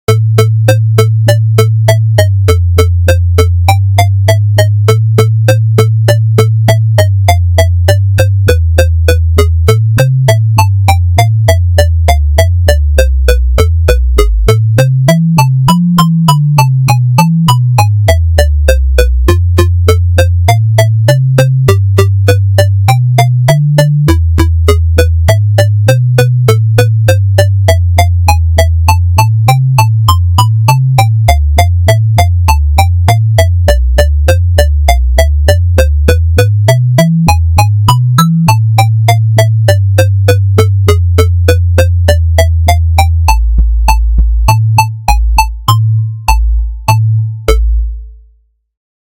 レトロゲーム風。
ループ向きではないです。
BPM160